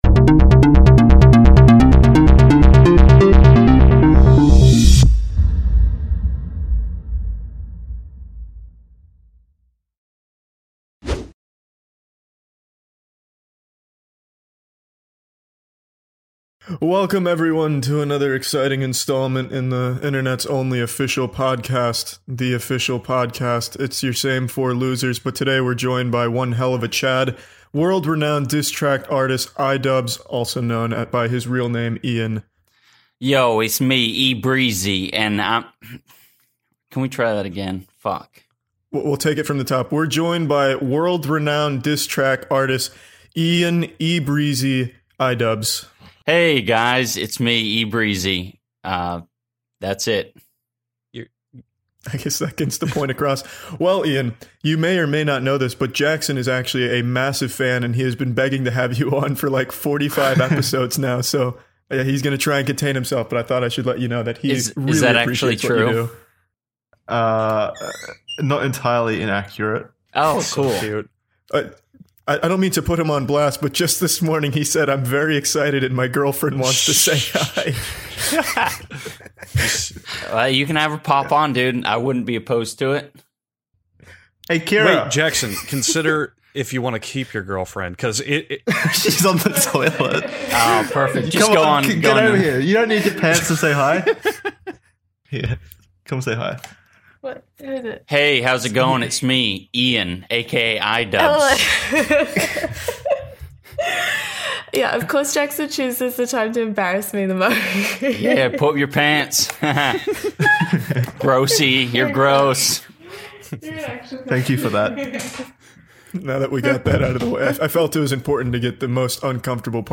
Five close man friends gather around to be content cops.